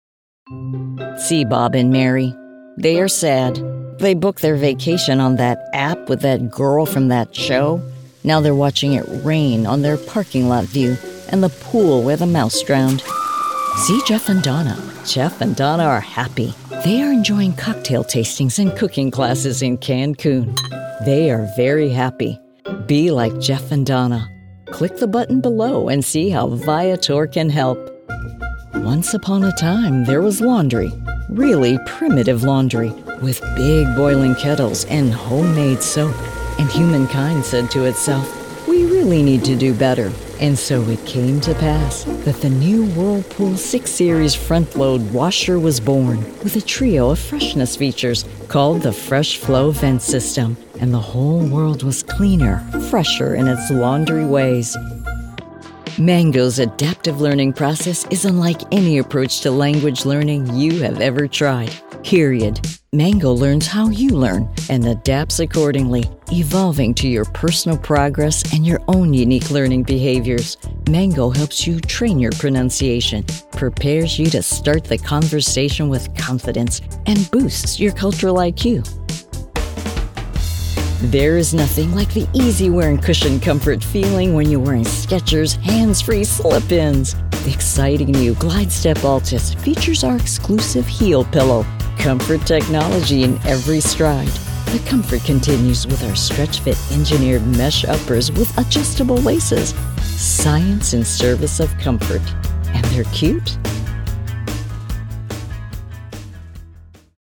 EXPLAINER Reel